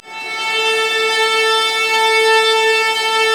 Index of /90_sSampleCDs/Roland L-CD702/VOL-1/STR_Vlas Bow FX/STR_Vas Sul Pont